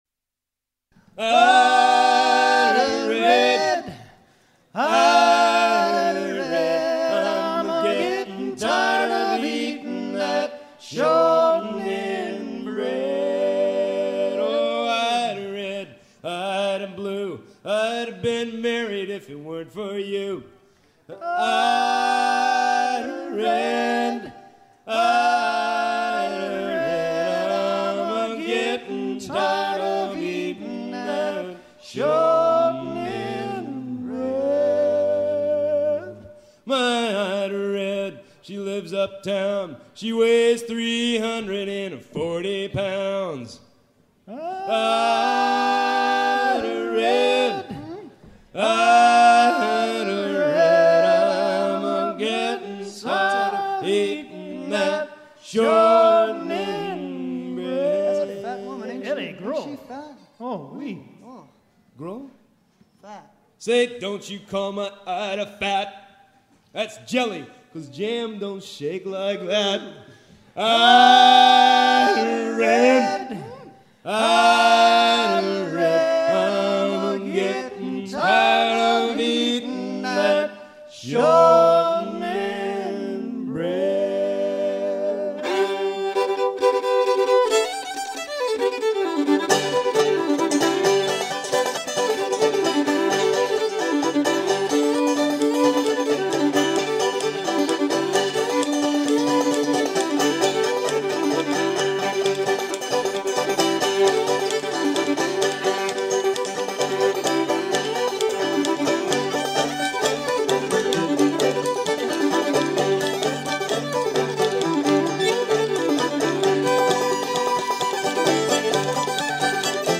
shanty utilisé par les arrimeurs de coton de Mobile en Alabama travaillant dans la cale des bateaux à vapeur
Pièce musicale éditée